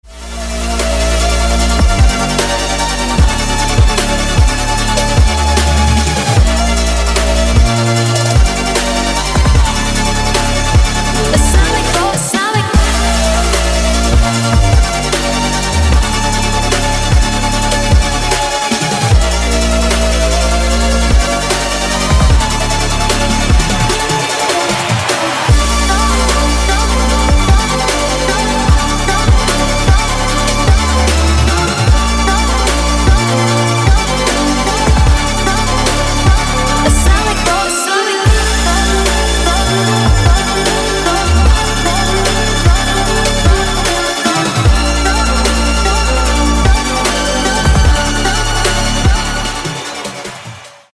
• Качество: 160, Stereo
dance
Electronic
EDM
Trap
future bass